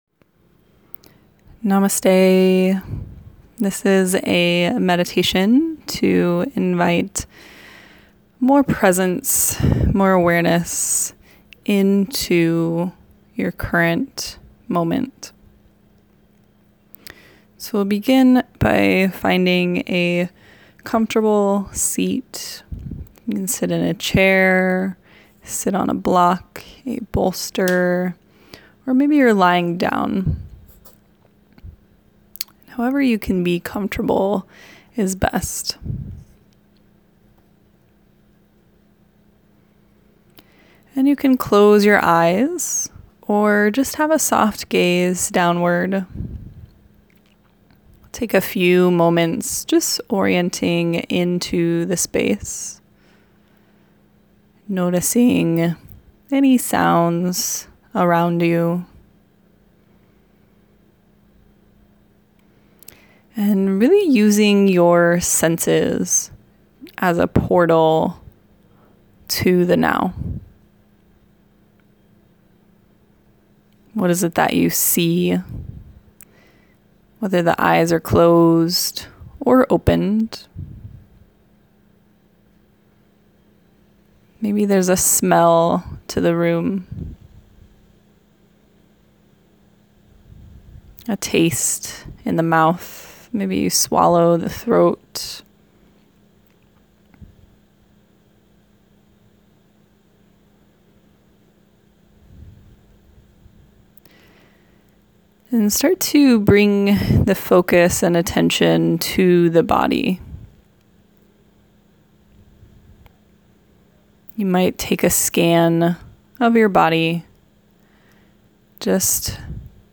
An 8-minute Present Moment meditation.